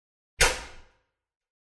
Звуки лампы и светильника_ Звук выбитых пробок электричества
• Категория: Лампы и светильники
• Качество: Высокое